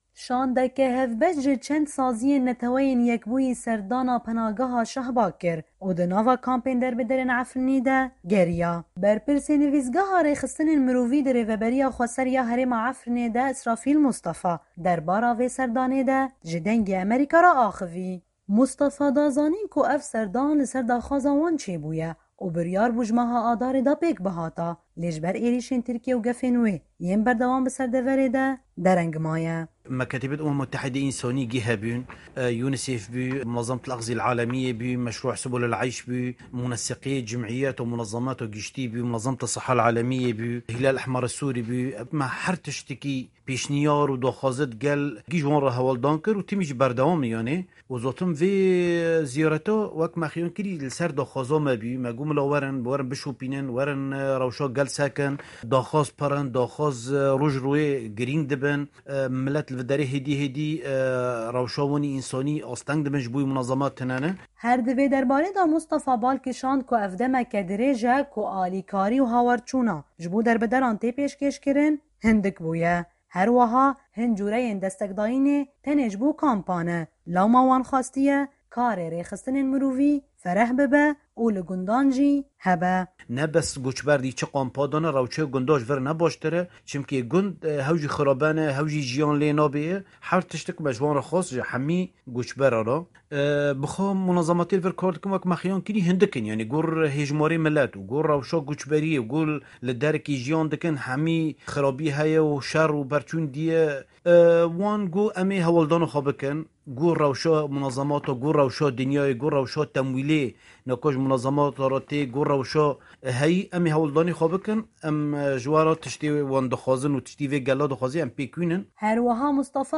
Berpirsê Nivîsgaha Rêxistinên Mirovî di Rêvebirîya Xweser ya Herêma Efrînê de Îsrafîl Mustefa derbara vê serdanaê de, ji Dengê Amerîka re axivî.